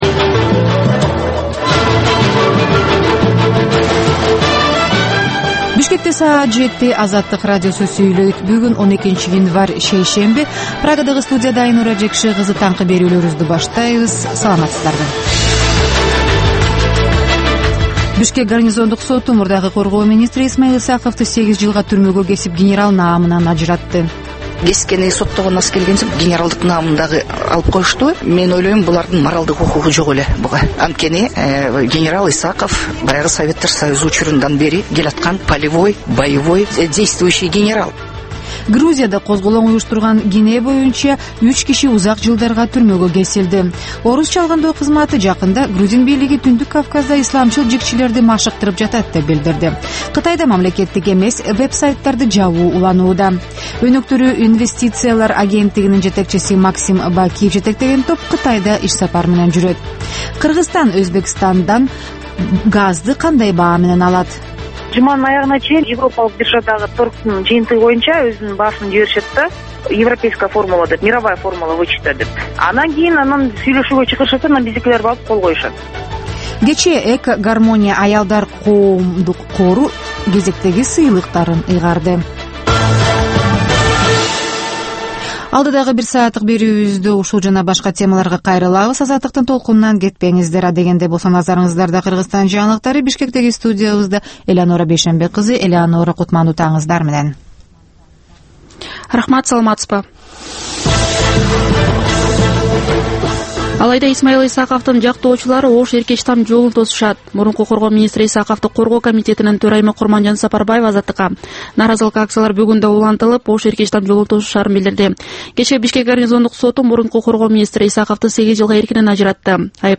Бул таңкы үналгы берүү жергиликтүү жана эл аралык кабарлардан, ар кыл окуялар тууралуу репортаж, маек, тегерек үстөл баарлашуусу, талкуу, баян жана башка берүүлөрдөн турат. "Азаттык үналгысынын" бул берүүсү Бишкек убактысы боюнча саат 07:00ден 08:00ге чейин обого чыгарылат.